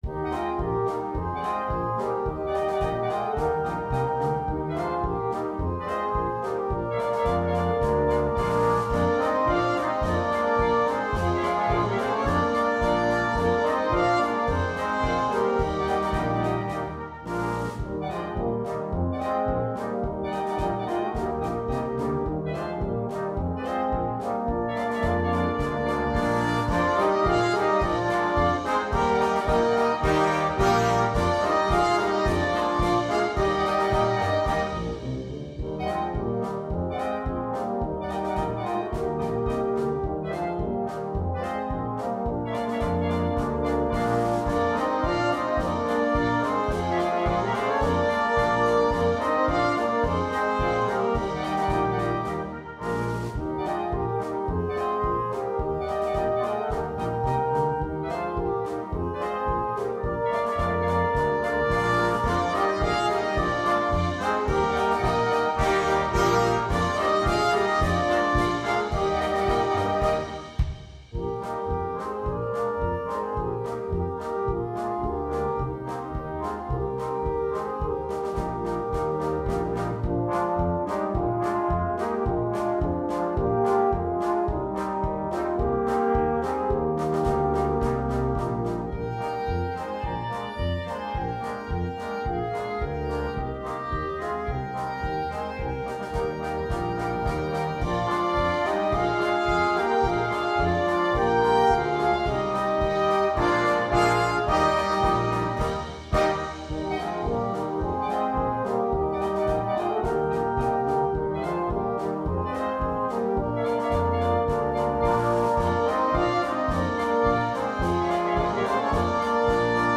Noten für Blaskapelle
komplette Besetzung
Polka, Weihnachtsmusik